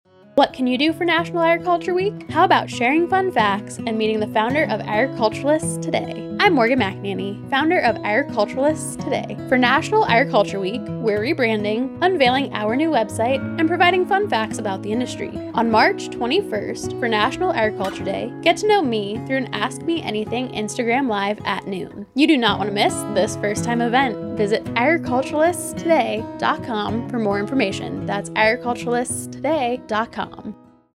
Radio Spot
radio-spot.mp3